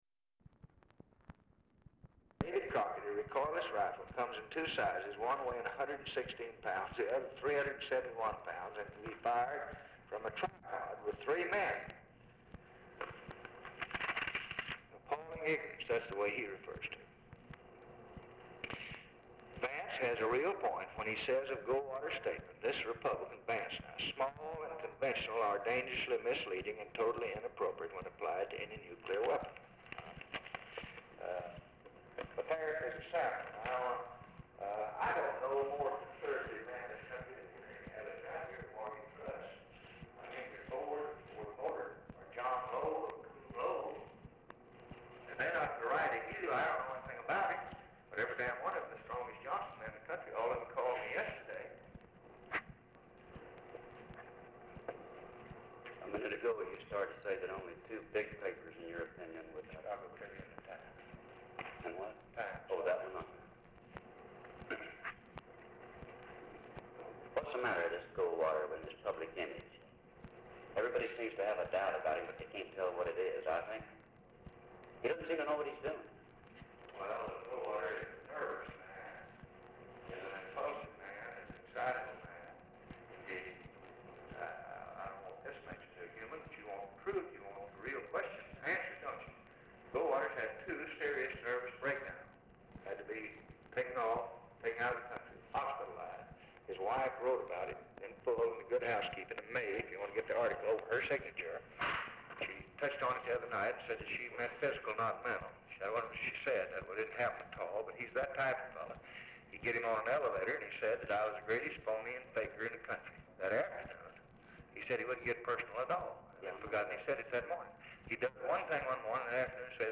BRIEF CONVERSATION BETWEEN UNIDENTIFIED FEMALES INTERRUPTS RECORDING
Format Dictation belt
Location Of Speaker 1 Oval Office or unknown location
Specific Item Type Telephone conversation